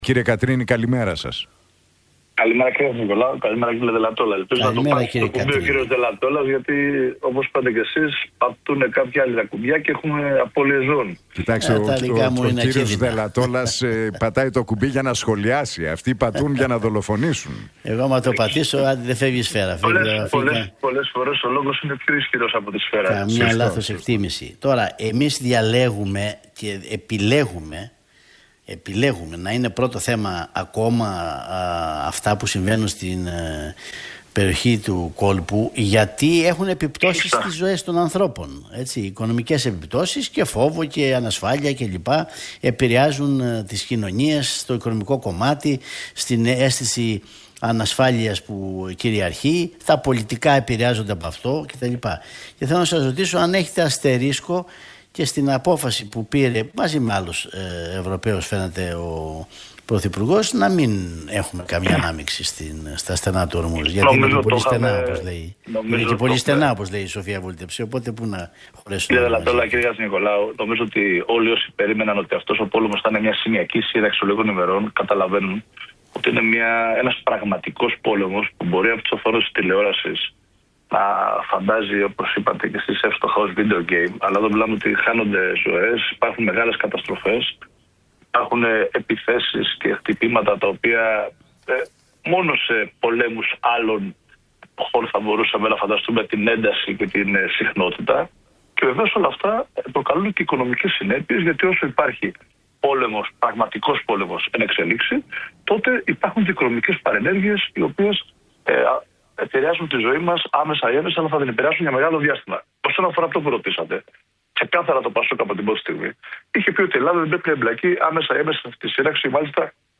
Κατρίνης στον Realfm 97,8: Εφικτός ο στόχος του ΠΑΣΟΚ για νίκη στις εκλογές - Πολιτικός αντίπαλος η ΝΔ, δεν μπορούμε να είμαστε σε κανένα τραπέζι συζητήσεων